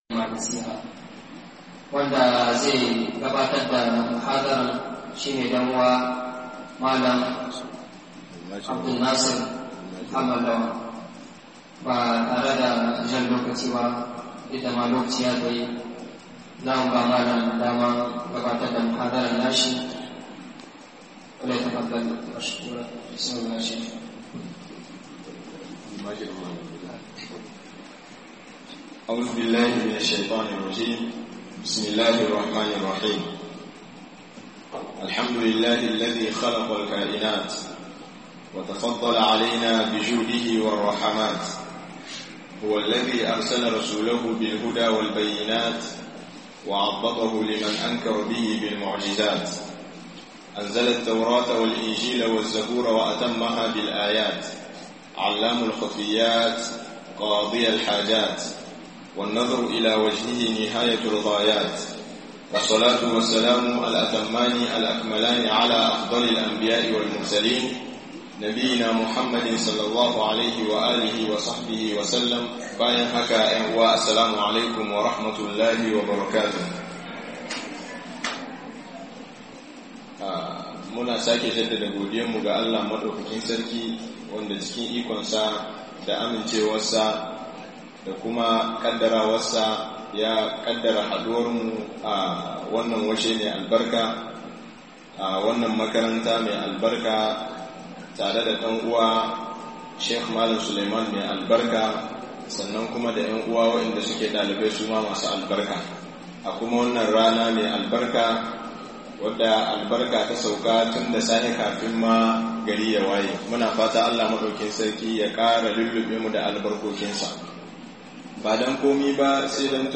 Matasa a Musulunci - Muhadara